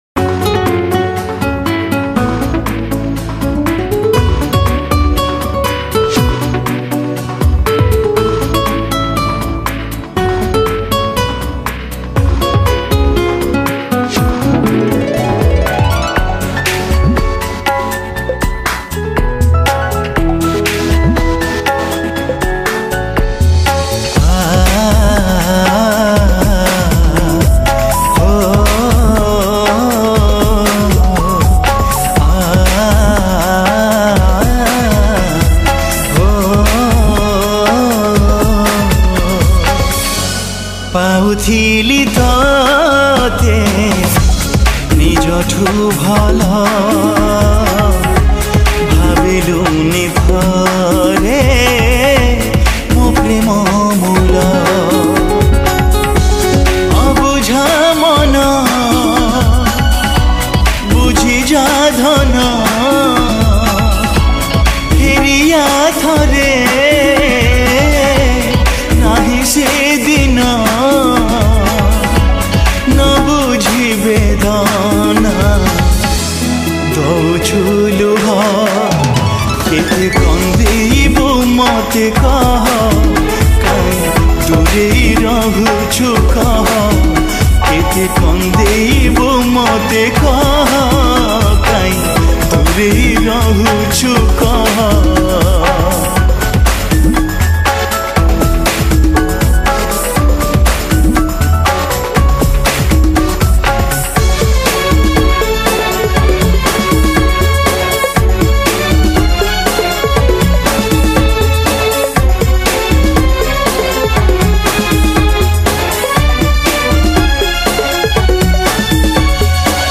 New Odia Sad Song